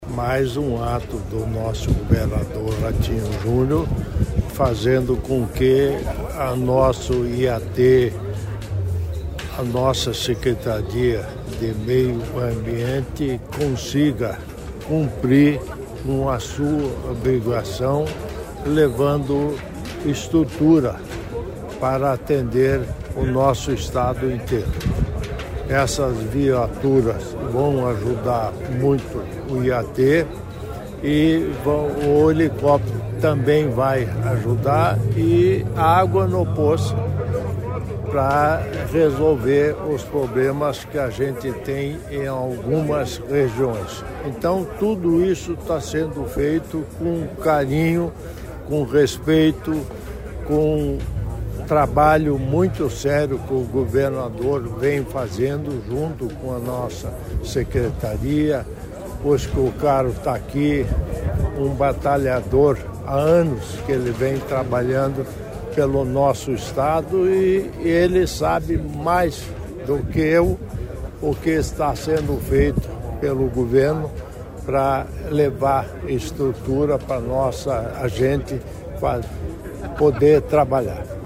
Sonora do vice-governador Darci Piana sobre as novas caminhonetes e helicóptero para o IAT